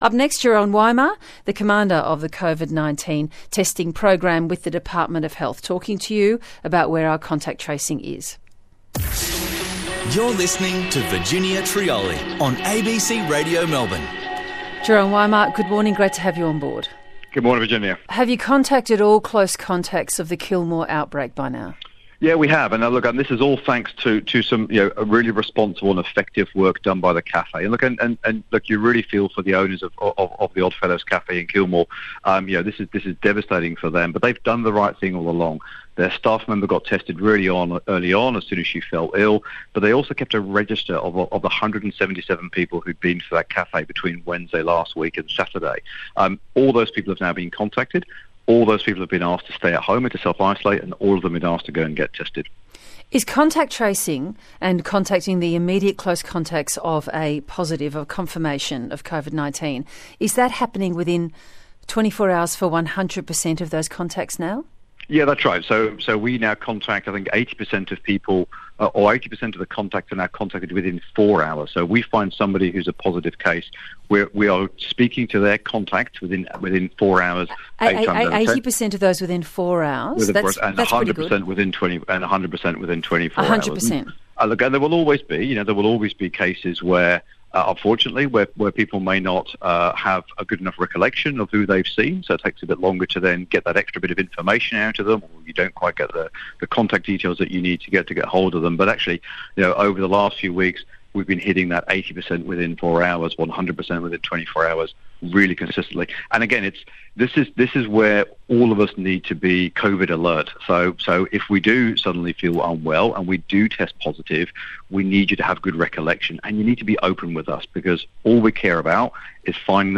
"Our testing numbers are looking solid," he told ABC Radio Melbourne.